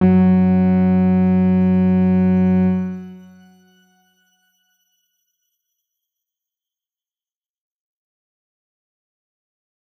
X_Grain-F#2-pp.wav